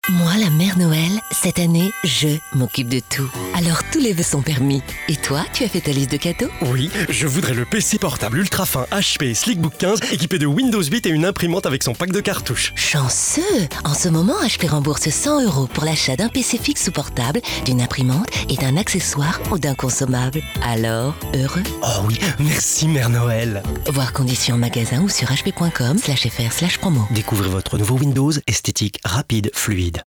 Pub Séphora personnage Maman
Comédienne, comédienne voix off ......et aussi formatrice en communication Orale et comportementale.